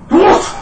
sneeze1.ogg